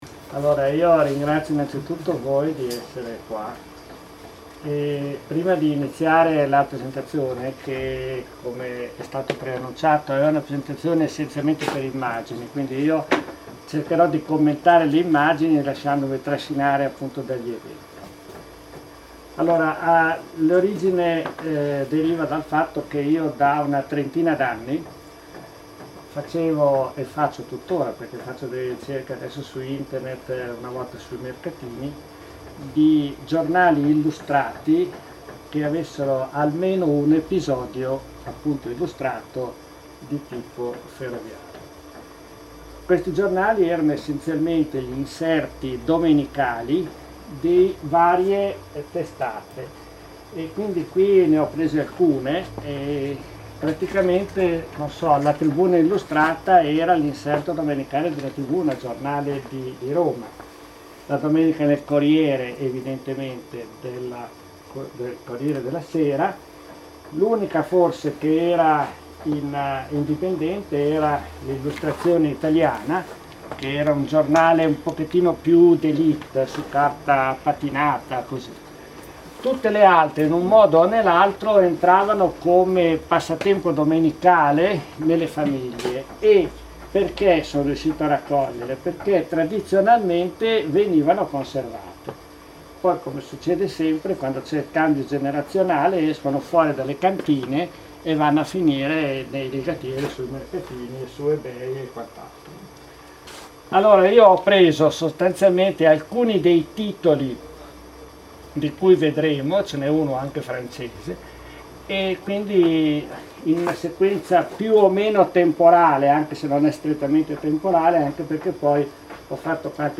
È disponibile la registrazione audio della conferenza del 30 novembre 2012: